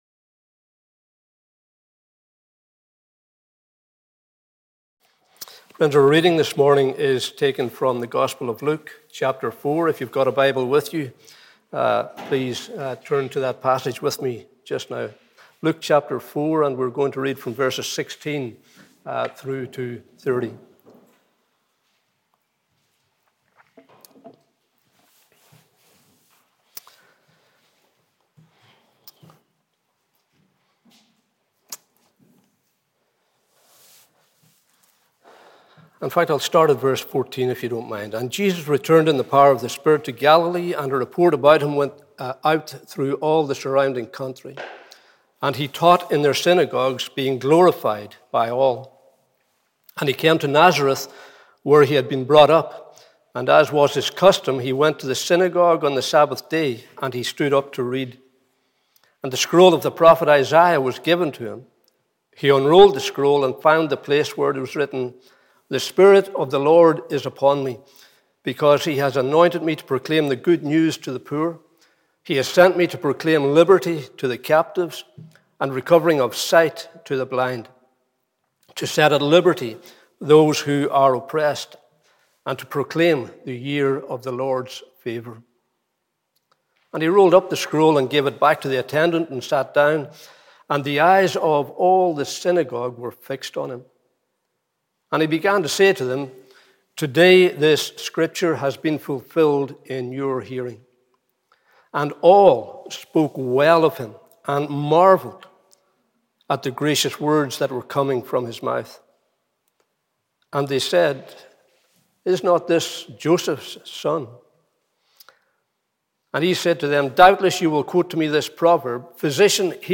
Morning Service 23rd May 2021 – Cowdenbeath Baptist Church